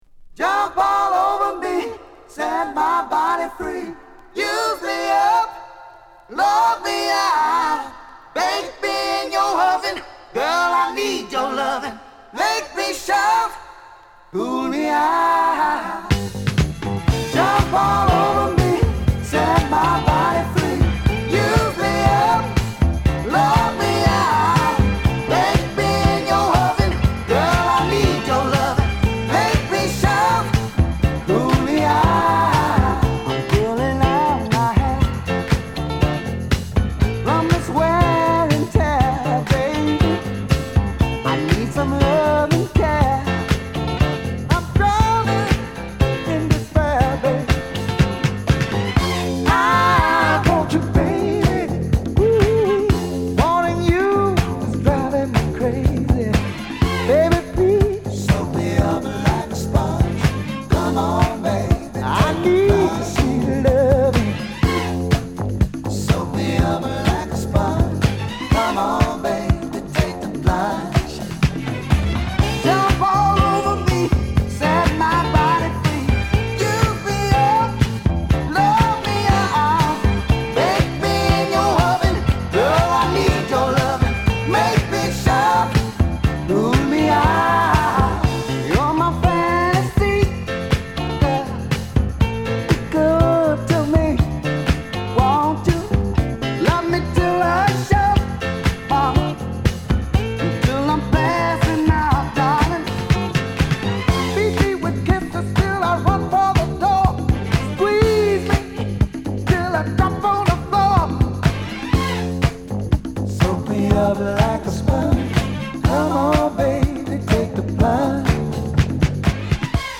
エモーショナルなアカペラから始まる小気味良いモダンダンサー！